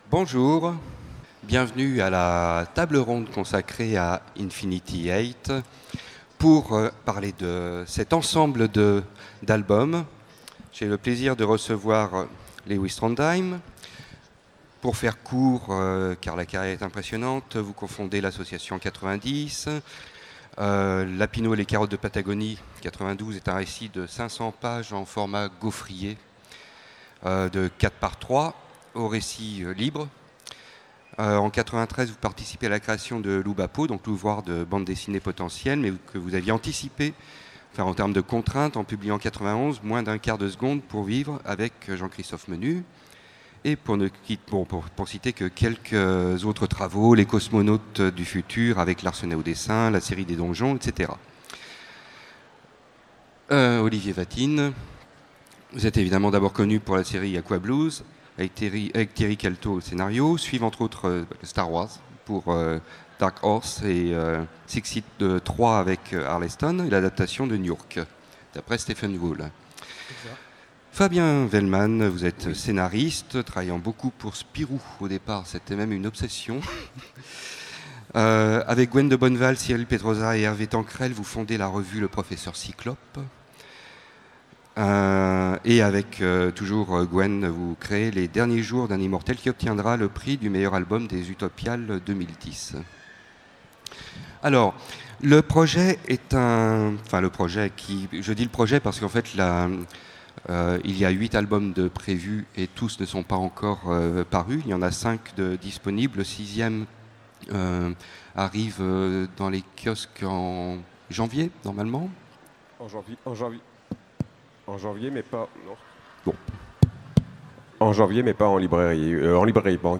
Utopiales 2017 : Conférence Infinity 8
Conférence